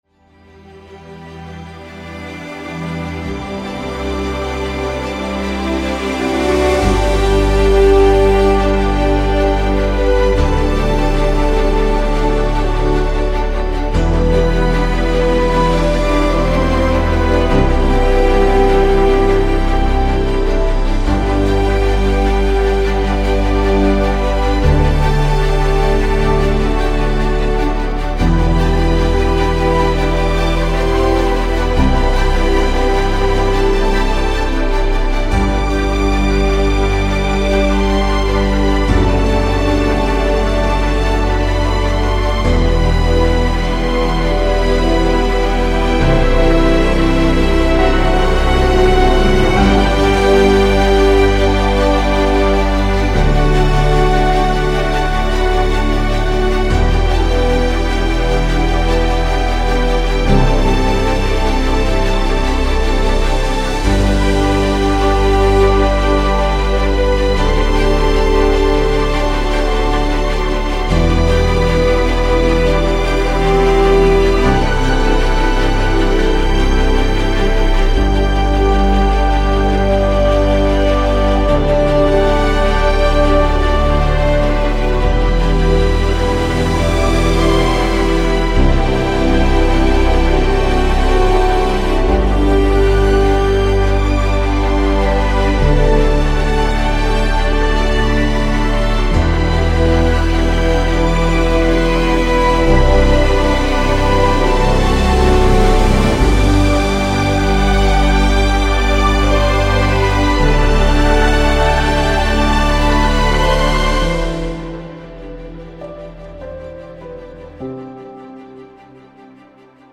Éther, guitare baveuse, nostalgie à réaction.